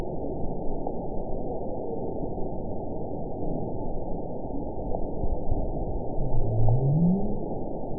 event 921789 date 12/19/24 time 02:11:29 GMT (11 months, 2 weeks ago) score 9.57 location TSS-AB03 detected by nrw target species NRW annotations +NRW Spectrogram: Frequency (kHz) vs. Time (s) audio not available .wav